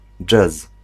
Ääntäminen
IPA: /d͡ʐɛs/